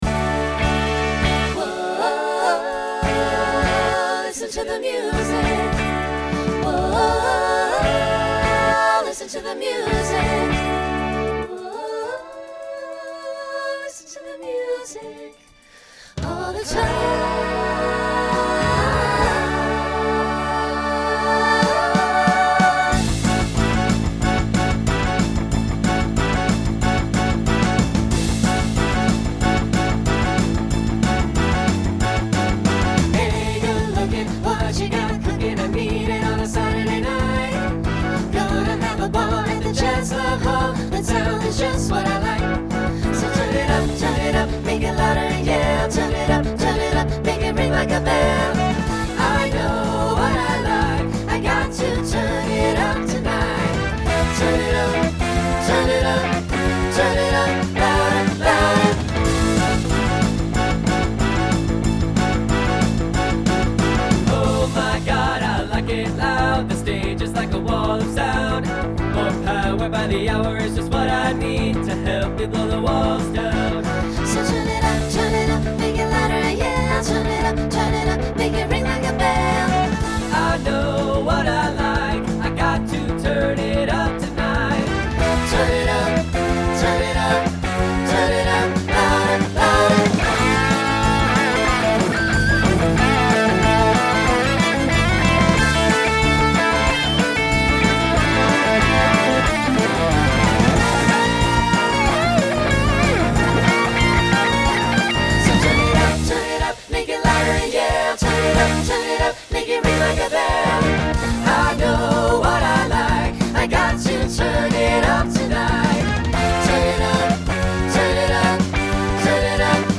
Decade 1970s Genre Rock Instrumental combo
Voicing SATB